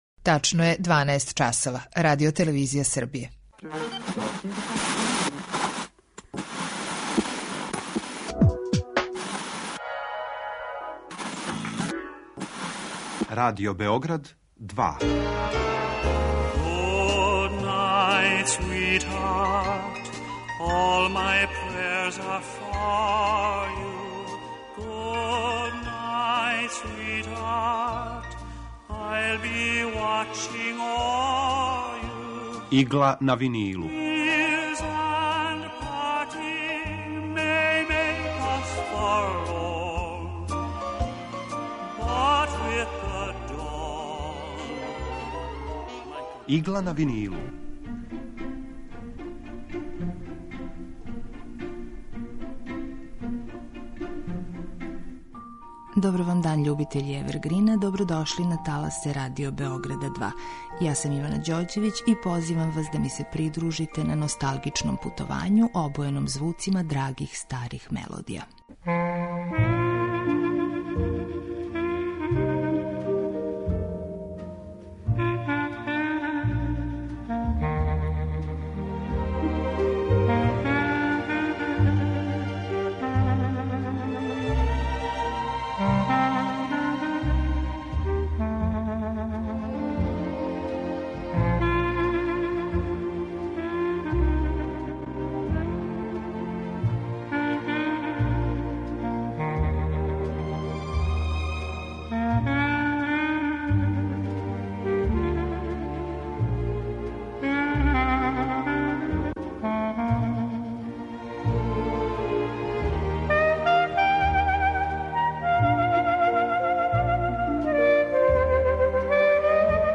Композиције евергрин музике